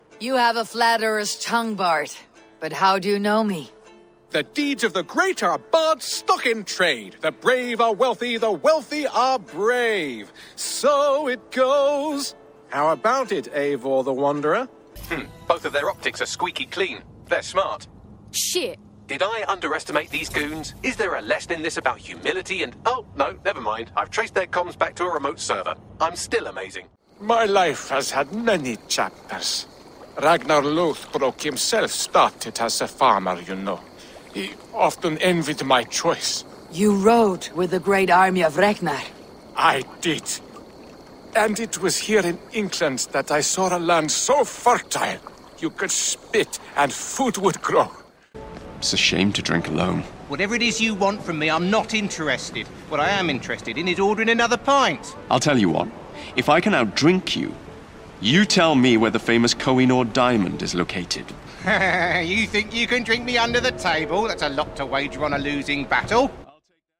Male
Assured, Versatile, Witty
BOOTHTEST_SENNEHISERMK4_OUTSPOKEN.mp3
Microphone: Sennheiser Mk4 | Shure Sm7b
Audio equipment: Soundbooth, Steinberg UR22C, Scarlett Focusrite 2i2